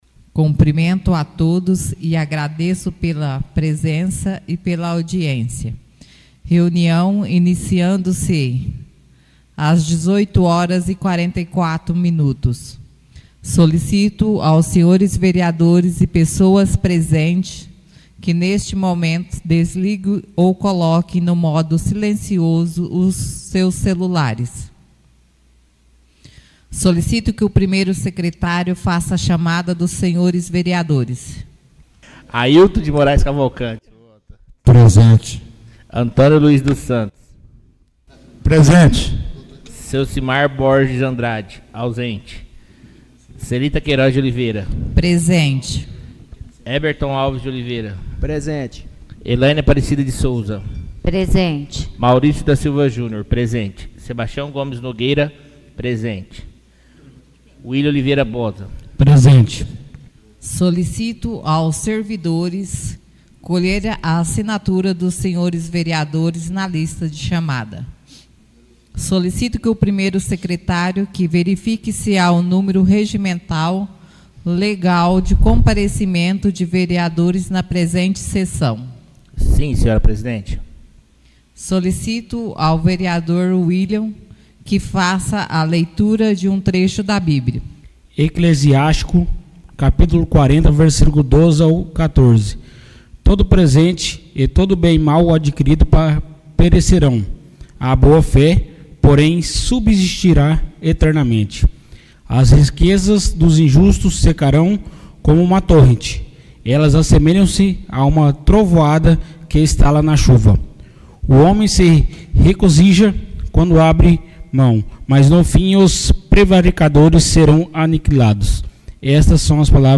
Reuniões Extraordinárias